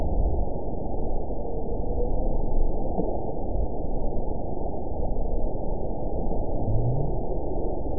event 921163 date 05/01/24 time 05:37:25 GMT (1 year ago) score 8.94 location TSS-AB04 detected by nrw target species NRW annotations +NRW Spectrogram: Frequency (kHz) vs. Time (s) audio not available .wav